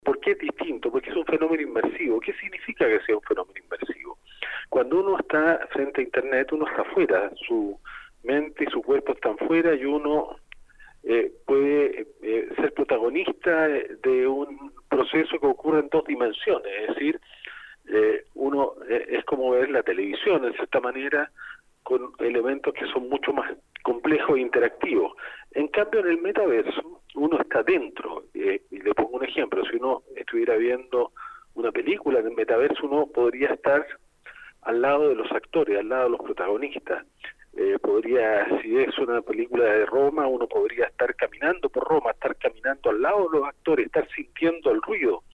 En entrevista con Radio UdeC, Guido Girardi, vicepresidente ejecutivo de Fundación Encuentros del Futuro y principal impulsor del Congreso Futuro, destacó la posibilidad de contar con un espacio de discusión social en un periodo en que la tecnología avanza más rápido que la capacidad humana de adaptarse y regularla.